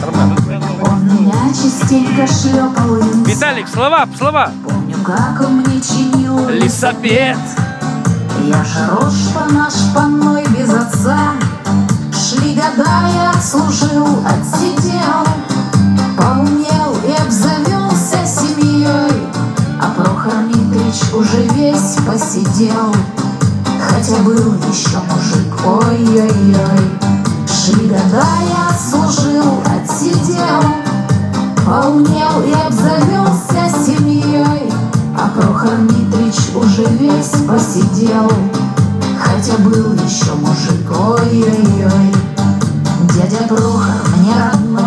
Звук гульбы